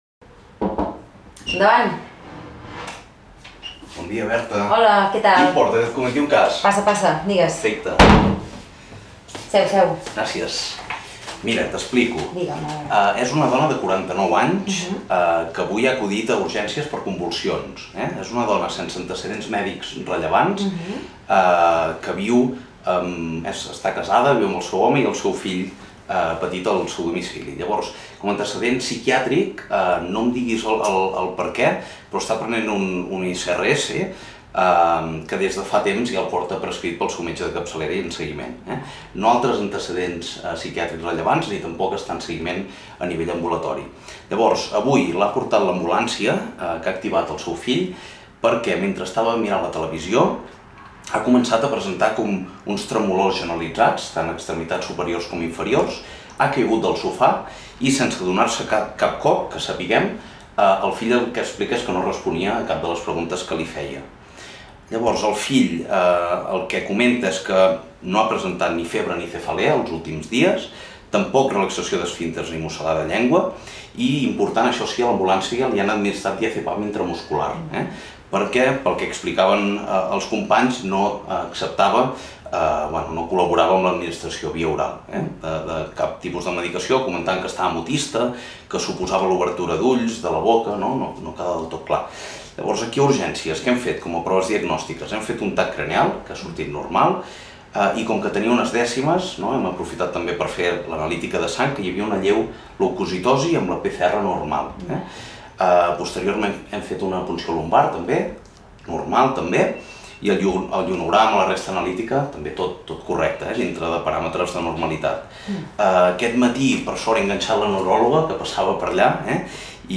Simulació d’una cas a la consulta d'urgències de salut mental per treballar l’aprenentatge basat en problemes (ABP) al Grau d’Infermeria